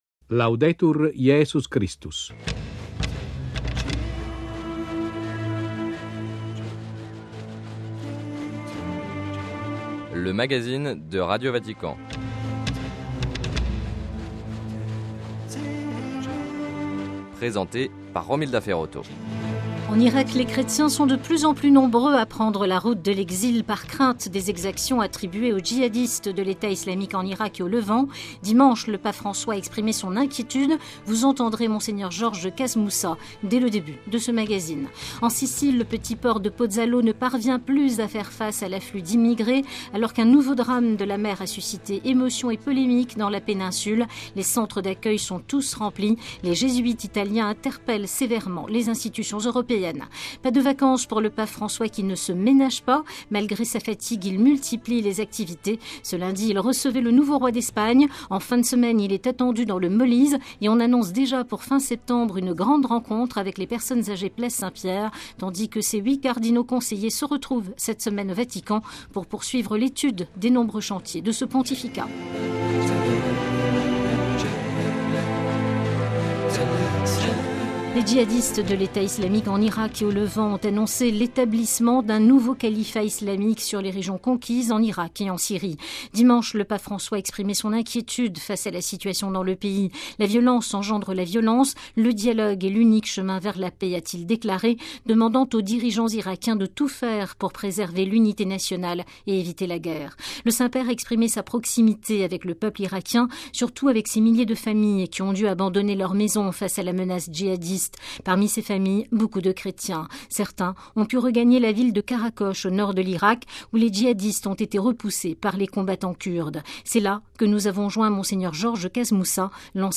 Sommaire : - Les djihadistes de l'EIIL annonçent l'établissement d'un califat islamique : entretien avec Mgr Georges Casmoussa, archevêque syro-catholique émérite de Mossoul. - Nouvelle attaque de Boko Haram contre des églises au Nigéria.